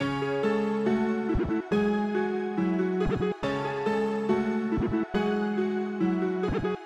Metro_loopC#m (3).wav